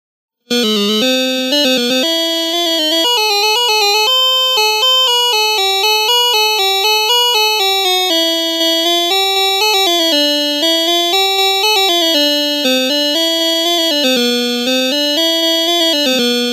Download Classic Video Game sound effect for free.
Classic Video Game